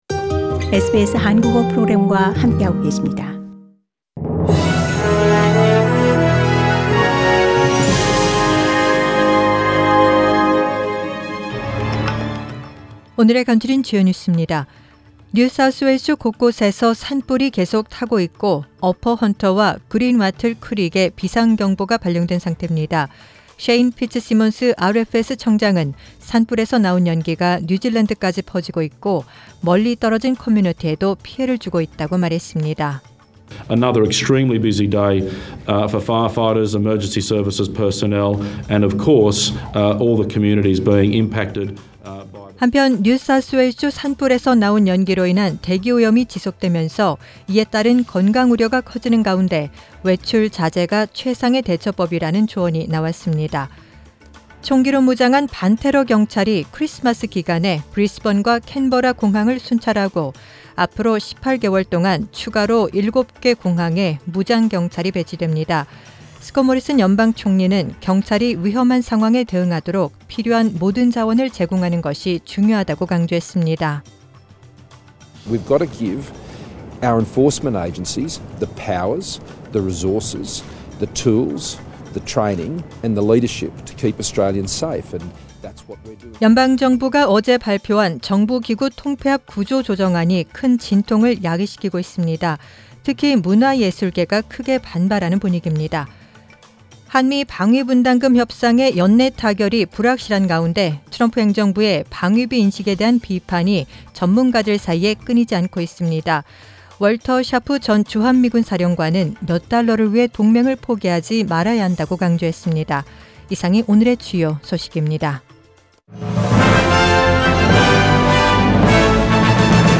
Major stories from SBS Korean News on Friday, 06 December